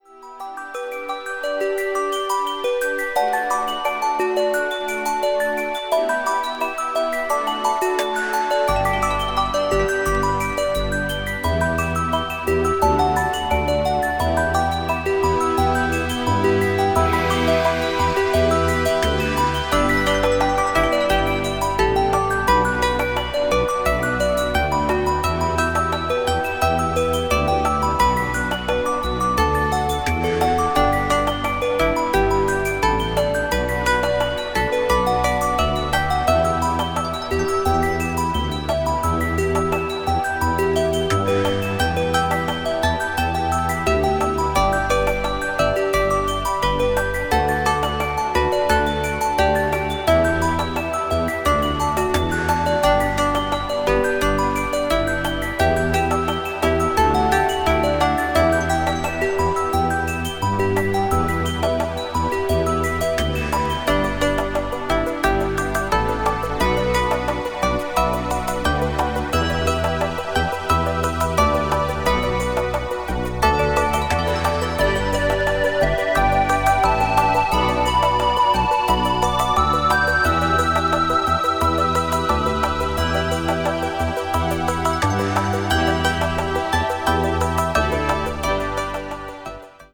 media : EX/EX(some slightly noise.)
ambient   german electronic   new age   synthesizer